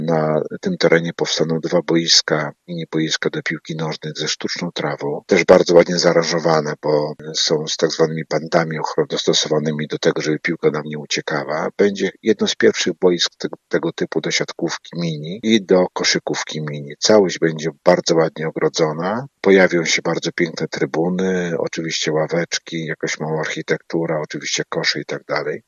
Wójt Gminy, Dariusz Łukaszewski mówi, że te obiekty będą bardzo nowoczesne.